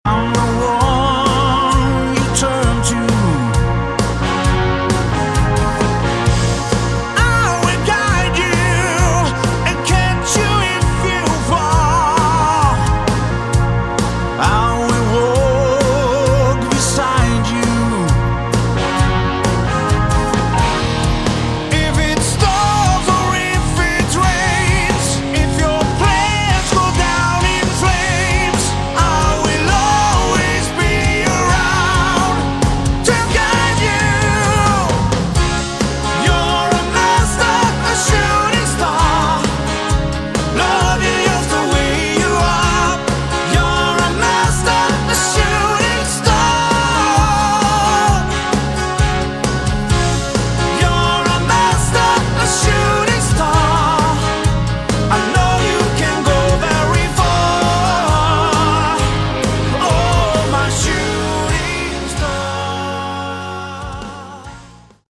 Category: Melodic Rock
lead vocals
guitar, bass, keyboards, backing vocals
drums, additional Keyboards, backing vocals